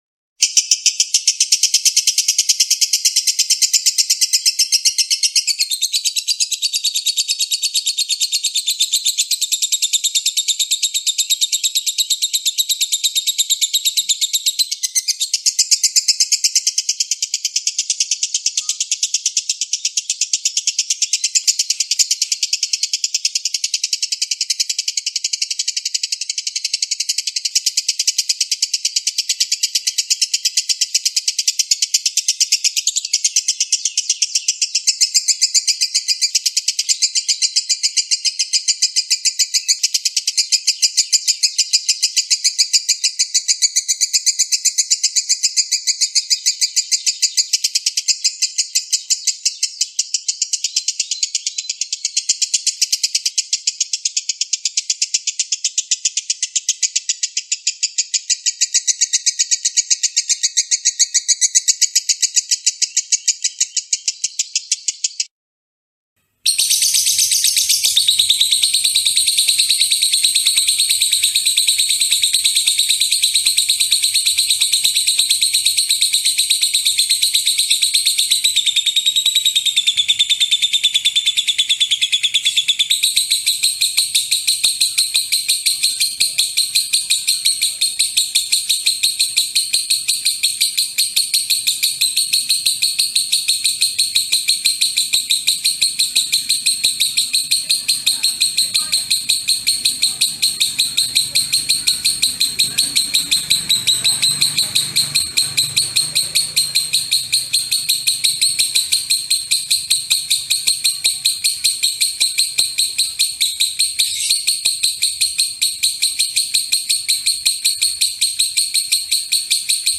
Download suara burung lovebird juara 1 nasional dan internasional ngekek durasi panjang di sini! Cocok buat masteran lovebird biar gacor juara.
Suara Burung Lovebird Juara Internasional 2026
Tag: suara burung hias suara burung Lovebird suara kicau burung
suara-burung-lovebird-juara-internasional-id-www_tiengdong_com.mp3